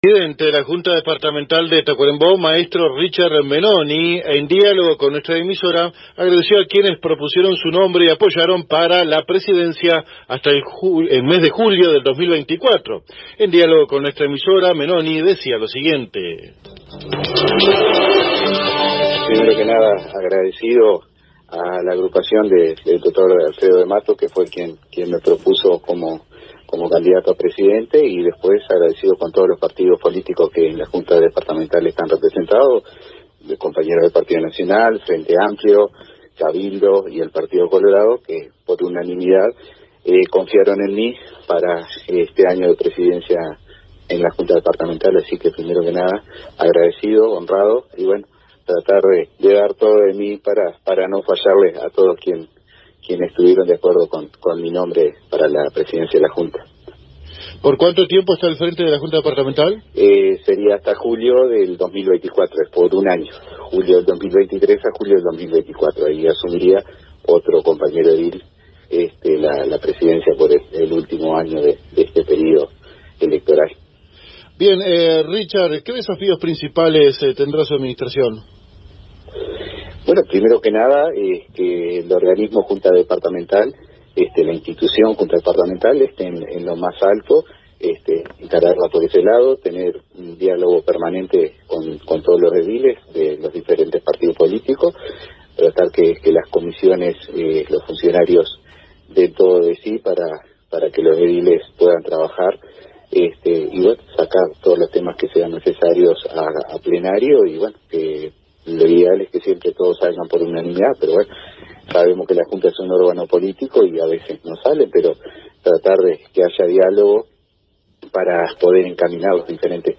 El nuevo presidente de la Junta Departamental de Tacuarembó, Mtro. Richard Menoni, conversó con los colegas de la AM 1110, sobre los diferentes desafíos que plantea su administración para los próximos 12 meses.